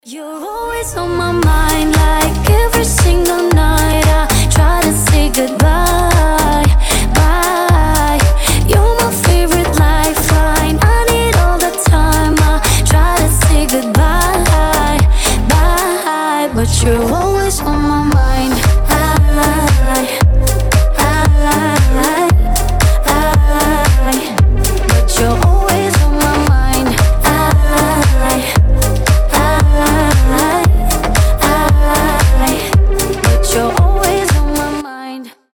Dance Pop
красивый женский голос